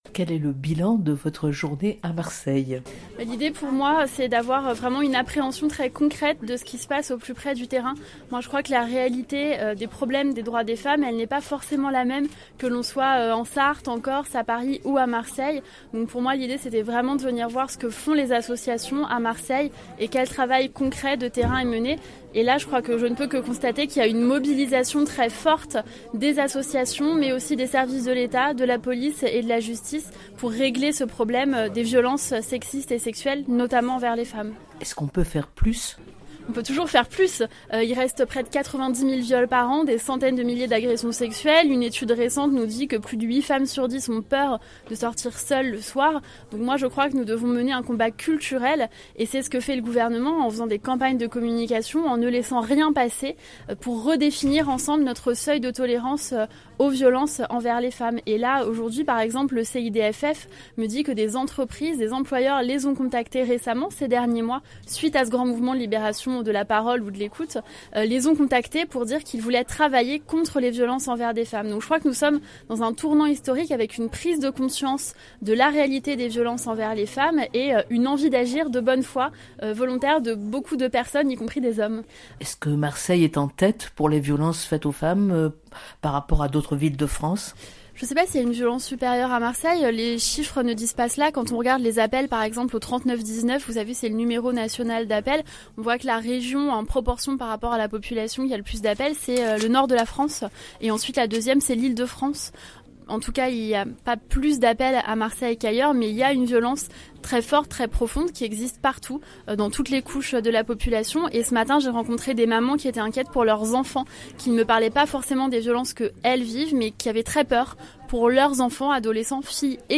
Entretien avec la ministre au sortir de ces huis clos